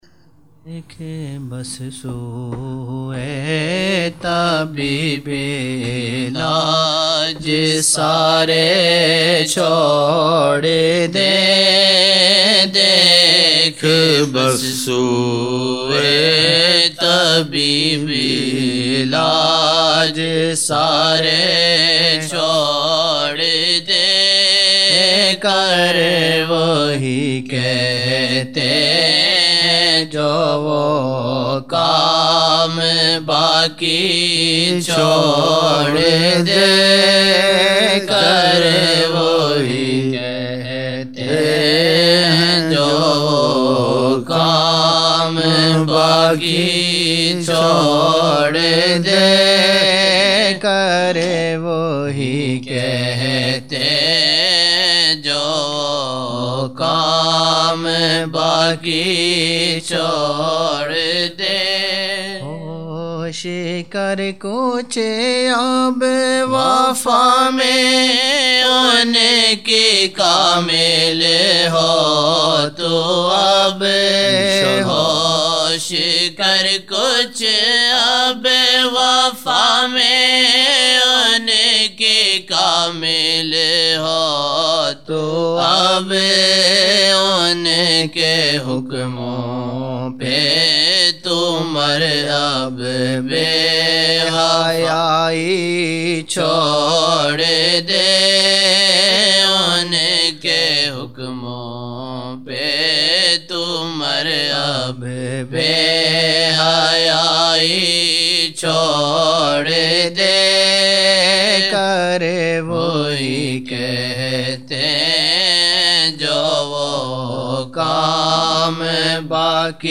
Maghrib
Naat shareef - Dekh bas sooe tabeeba illaj sarrey chor de) taleem shareef (duniya ki fikar chor kay Aaqa jee saw ki fikar karna) Download Now